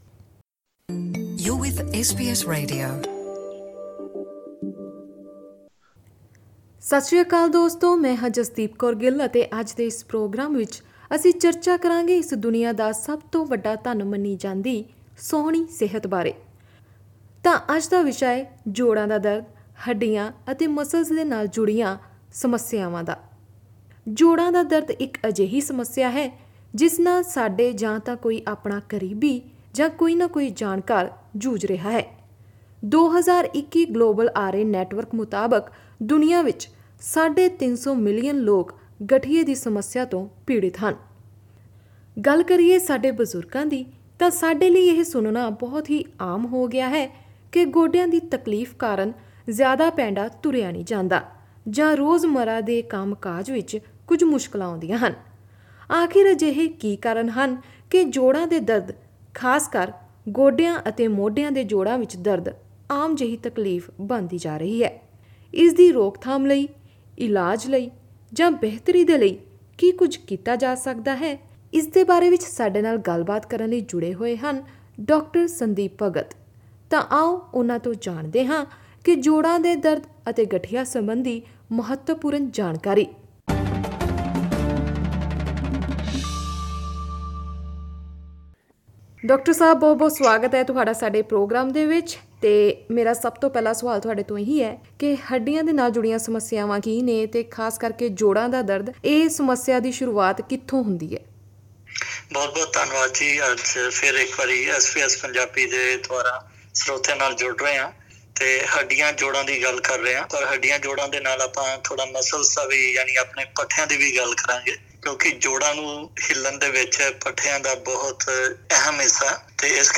Here we have a conversation with a medical expert who talks about the causes, symptoms and prevention measures for the common problem of joint pains and arthritis.